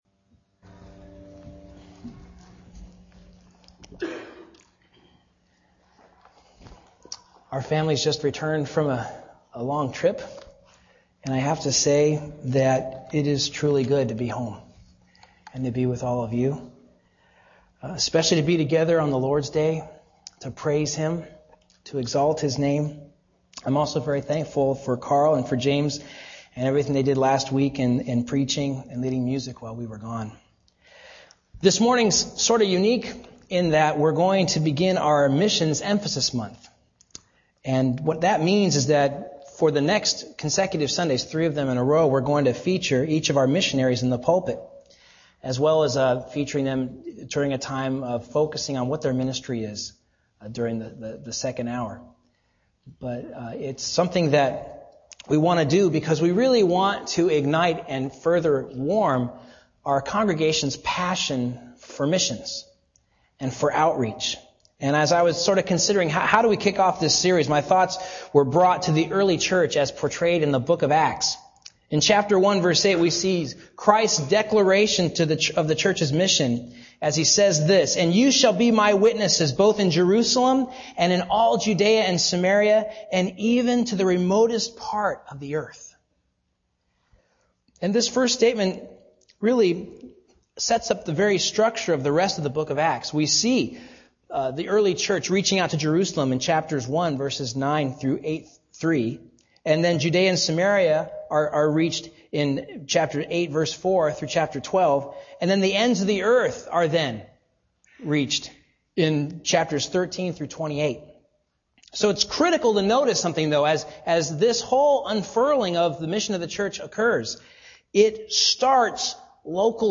This sermon introduces our 2007 Missions Emphasis Month (January 2007) focusing on Luke's account of Levi's evangelistic banquet. The contrast between the compassion of Christ and the fear of the Pharisees is explored.